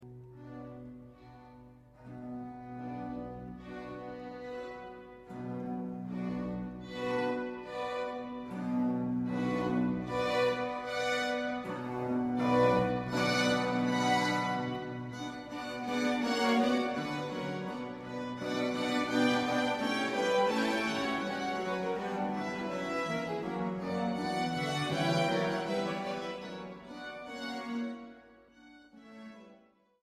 countertenor
tenor
bass
soprano
baritone
orchestra